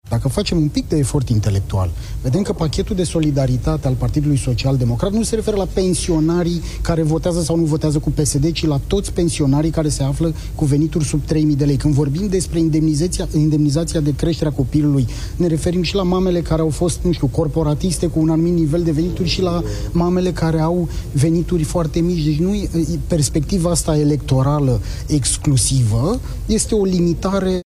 Ministrul Muncii, Florin Manole: „Nu este oferit din perspectiva asta electorală”